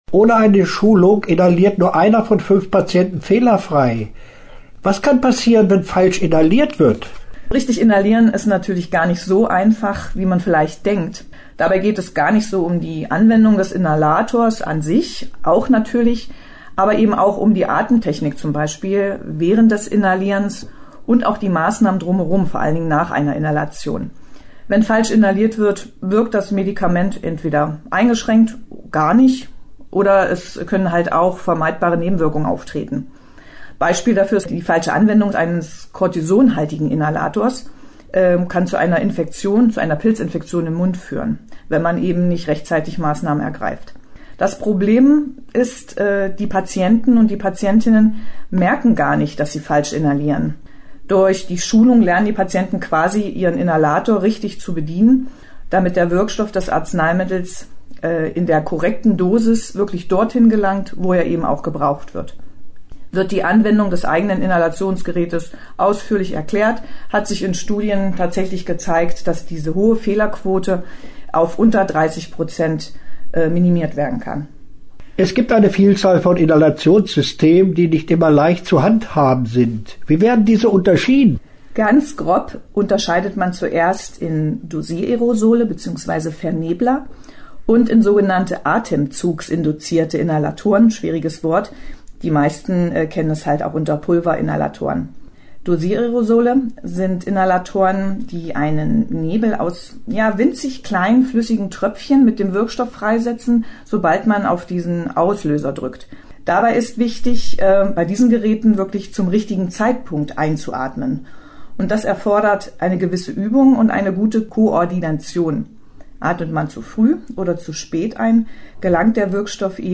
Interview-Inhalations-Schulung.mp3